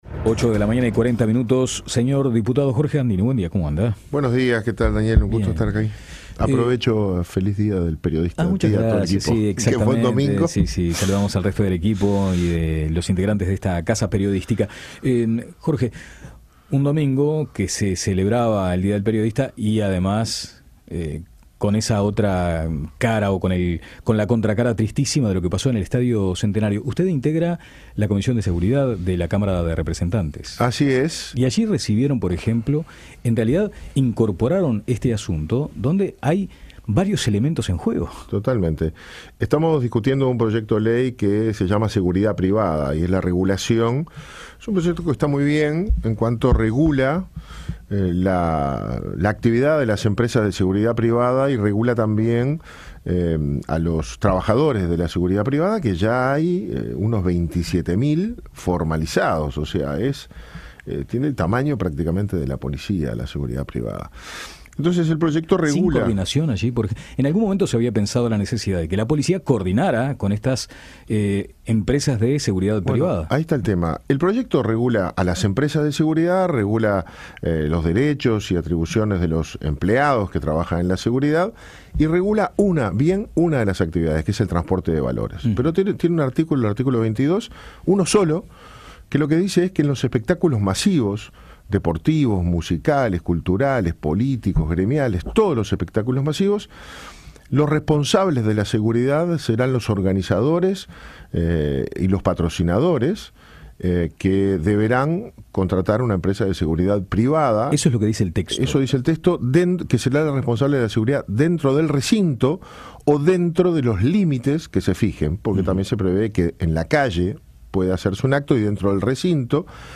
Entrevista a Jorge Gandini